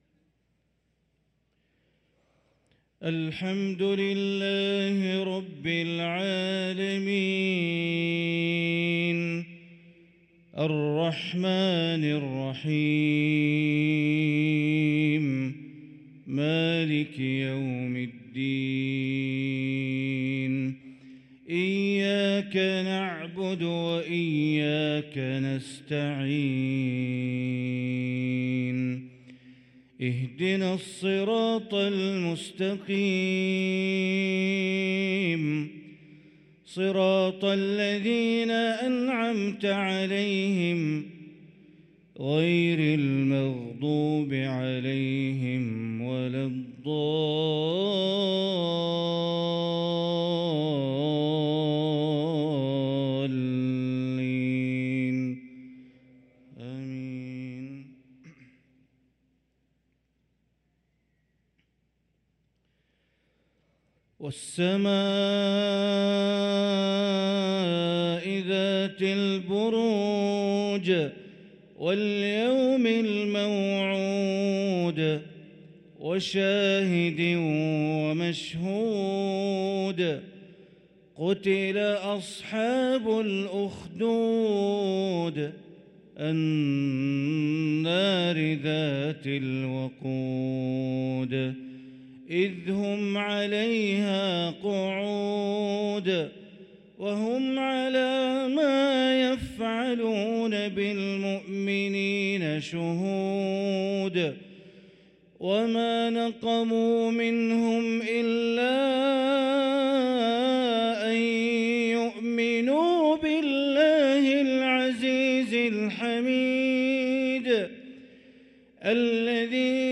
صلاة العشاء للقارئ بندر بليلة 16 صفر 1445 هـ